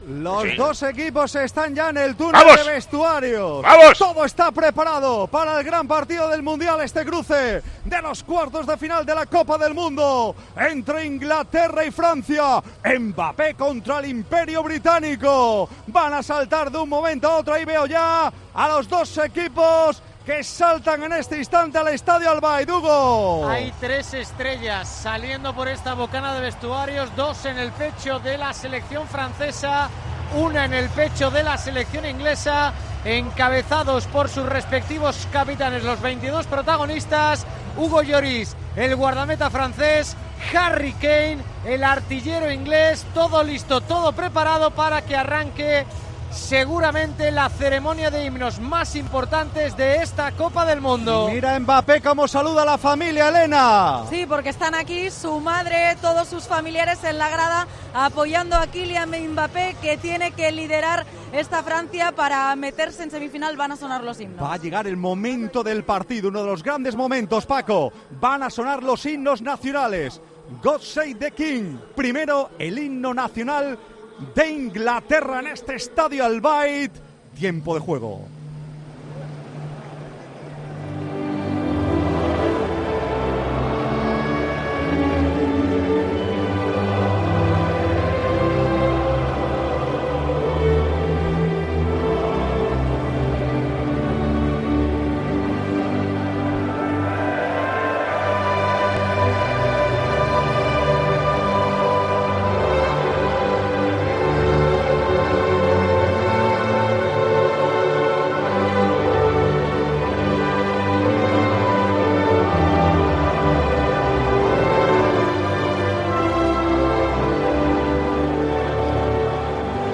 AUDIO: Así hemos vivido en 'Tiempo de juego' dos de los himnos más icónicos.